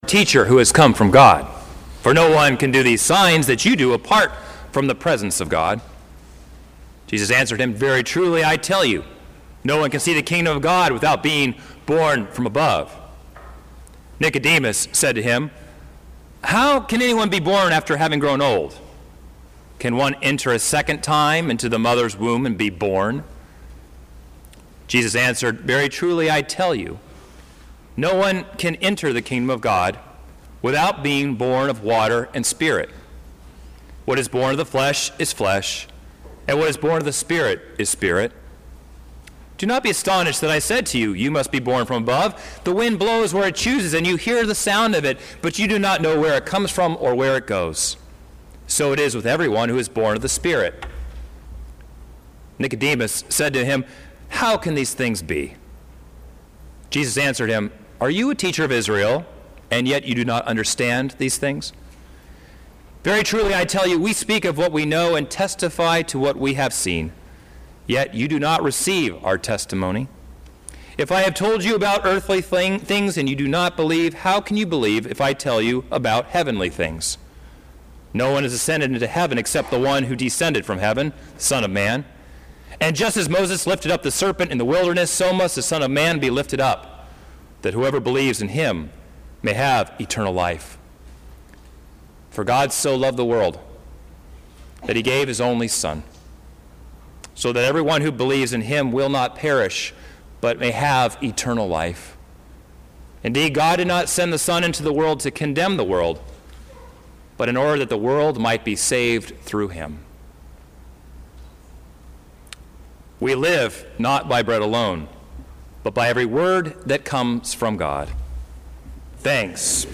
Sermon-3.19.17.mp3